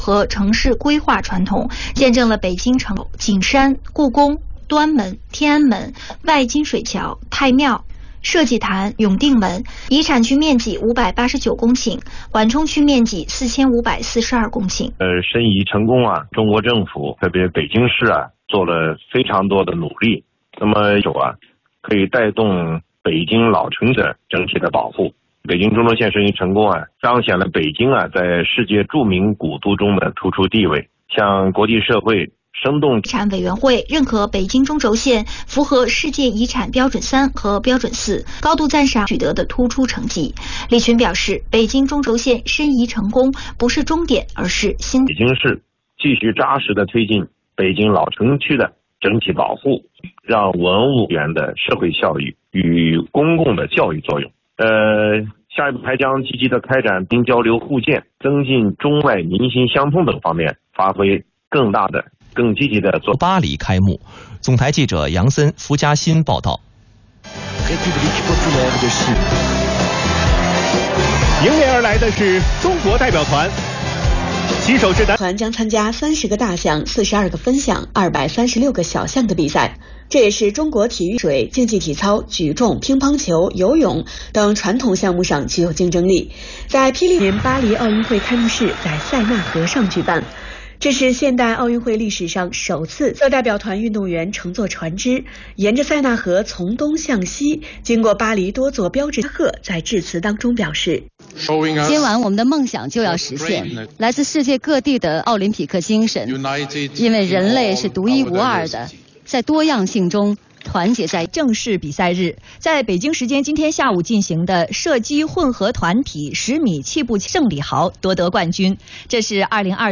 广播节目   2024-07-27 08:45 广播节目-陕西新闻广播